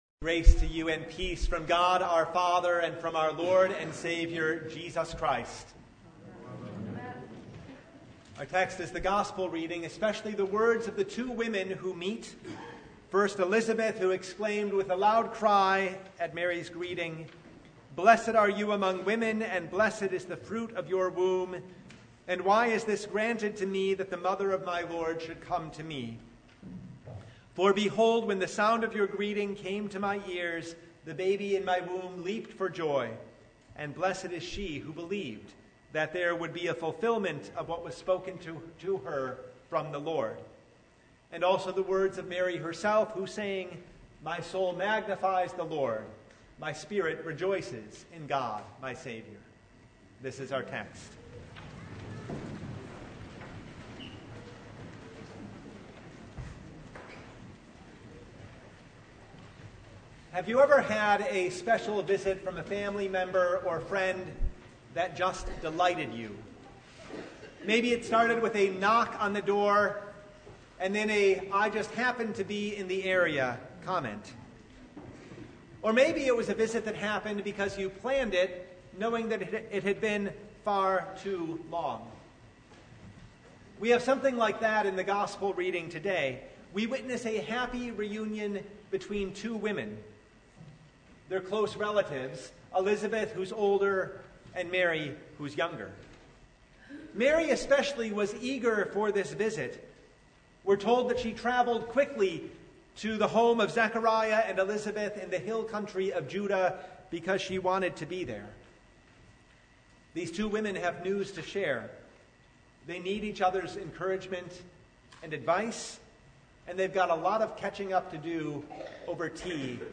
Luke 1:39–56 Service Type: Sunday In the run up to Christmas